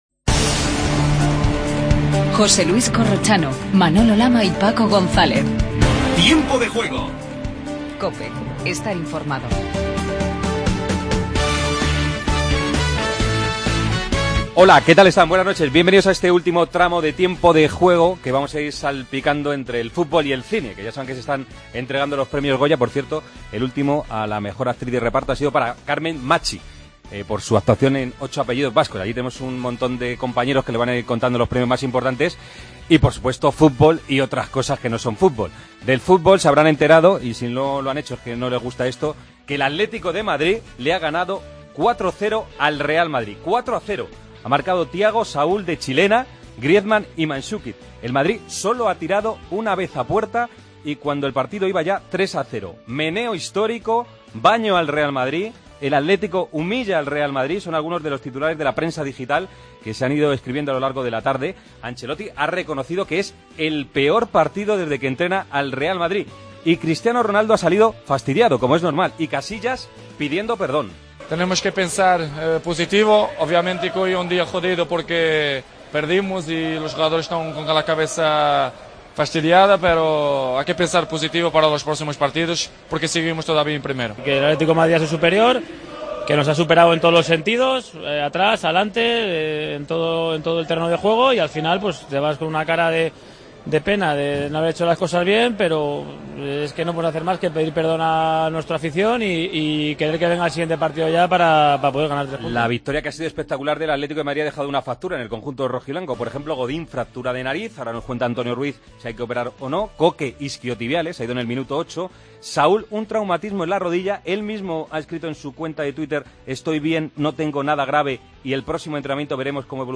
Análisis, crítica y tertulia sobre el derbi en el que el Atlético de Madrid ha 'apalizado' al Real Madrid. Breve paso por Anoeta para hablar del empate Real - Celta. Entrevista a Nolito.